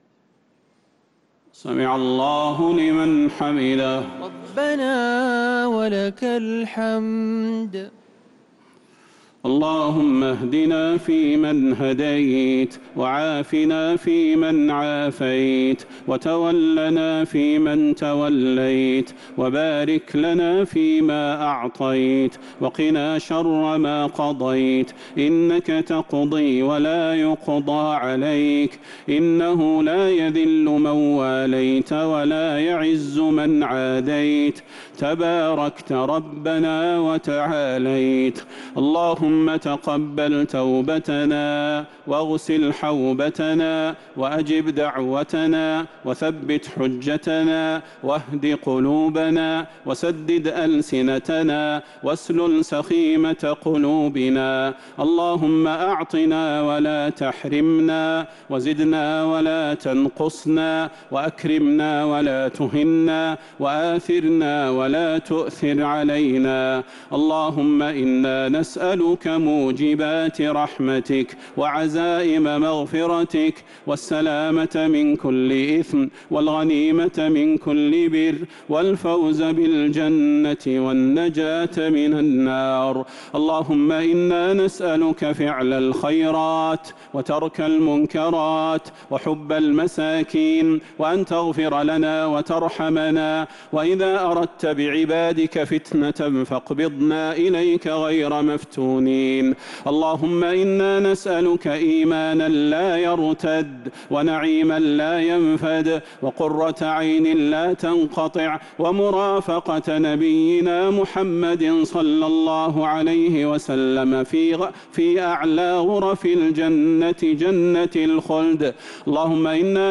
دعاء القنوت ليلة 21 رمضان 1447هـ | Dua 21st night Ramadan 1447H > تراويح الحرم النبوي عام 1447 🕌 > التراويح - تلاوات الحرمين